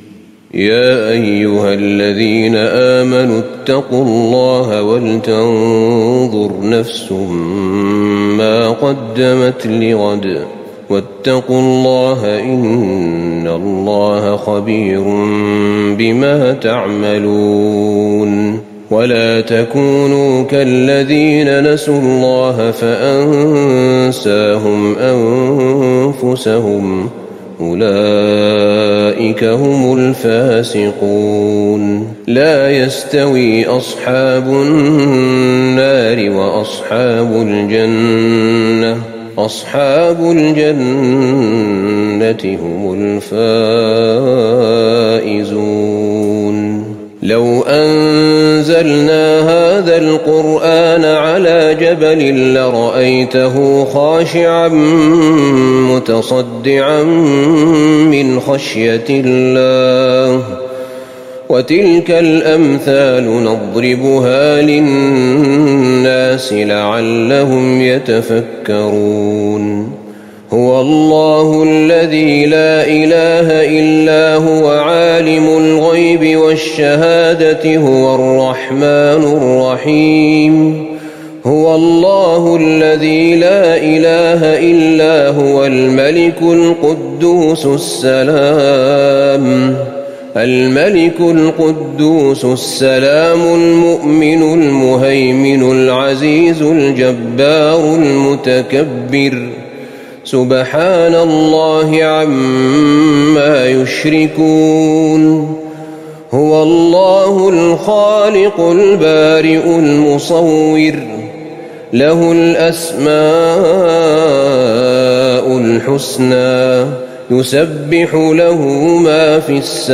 تهجد ٢٨ رمضان ١٤٤١هـ من سورة الحشر ١٨-النهاية والممتحنة والصف كاملتين > تراويح الحرم النبوي عام 1441 🕌 > التراويح - تلاوات الحرمين